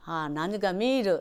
Aizu Dialect Database
Type: Statement
Final intonation: Falling
Location: Showamura/昭和村
Sex: Female